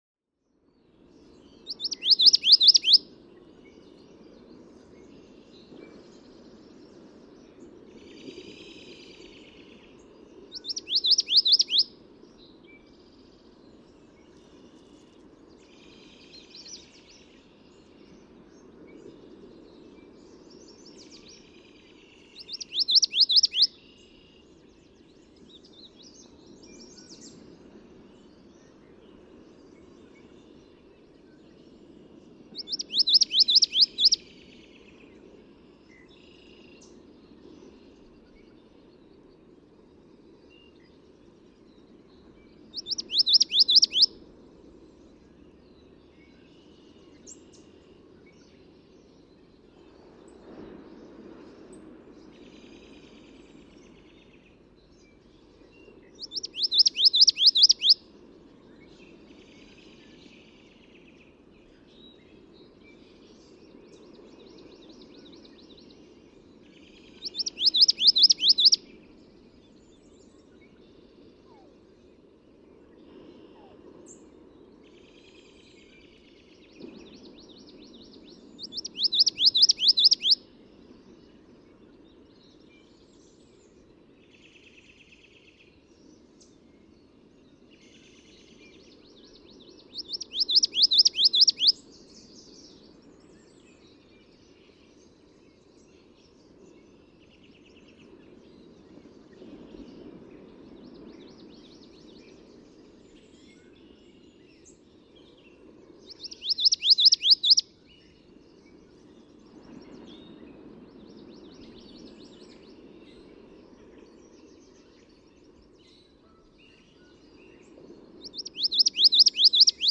May 21, 2014. Parker River National Wildlife Refuge, Newburyport, Massachusetts (with ocean waves).
♫214, ♫215—longer recordings from those two neighbors
214_Common_Yellowthroat.mp3